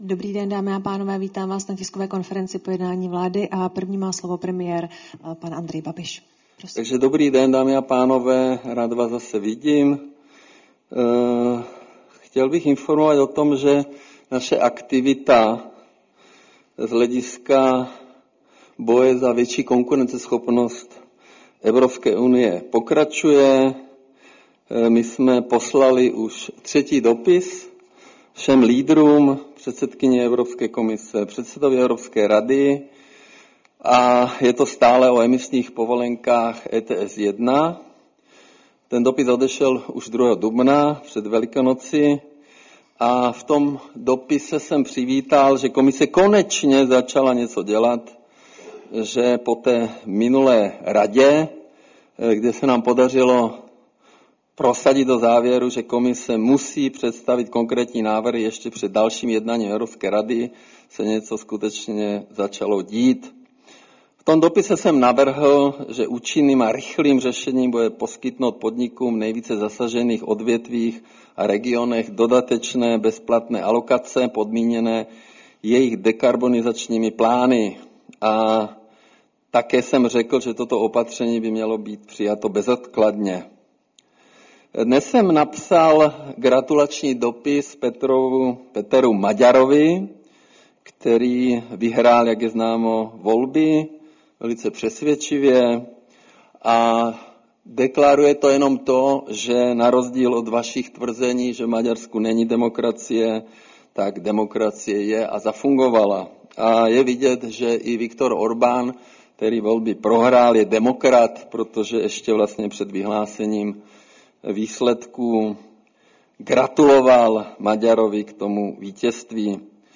Tisková konference po jednání vlády, 13. dubna 2026